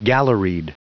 Prononciation du mot galleried en anglais (fichier audio)
Prononciation du mot : galleried